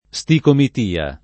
sticomitia